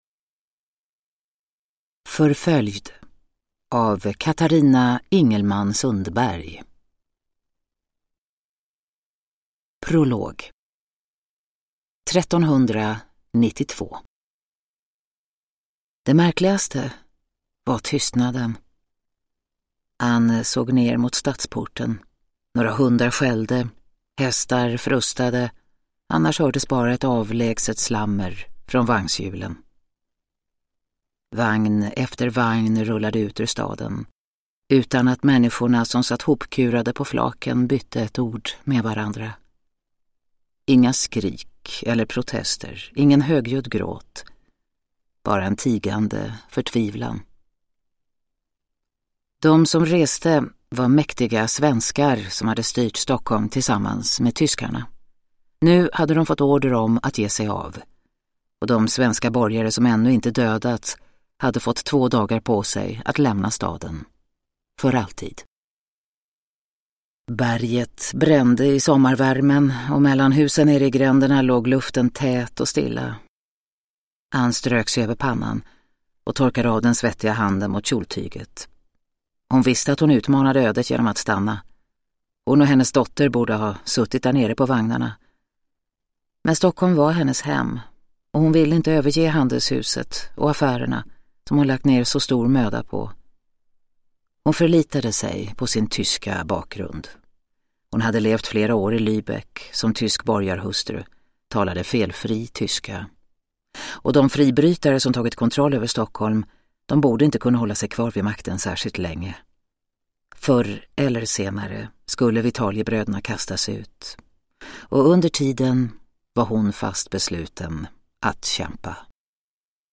Nedladdningsbar Ljudbok